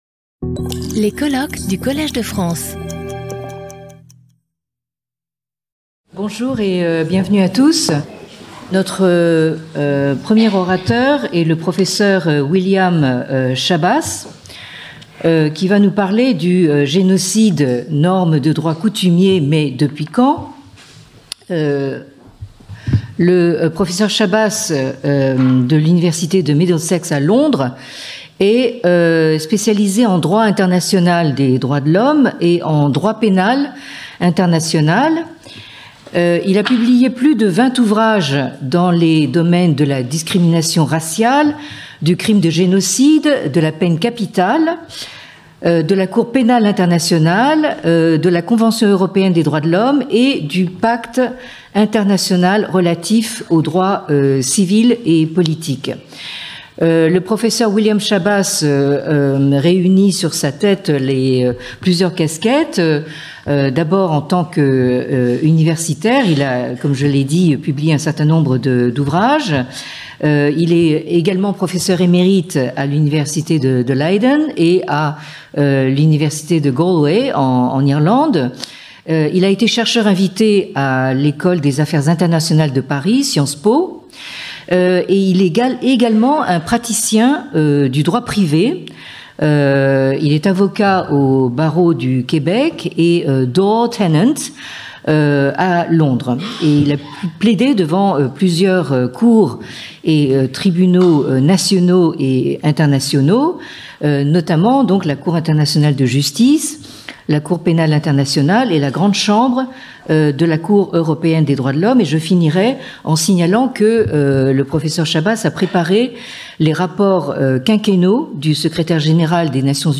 Speaker(s) William Schabas
Symposium 13 Jun 2025 09:30 to 10:00 William Schabas Genocide, a customary law norm, but since when ?